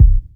Kicks
WU_BD_064.wav